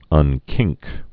(ŭn-kĭngk)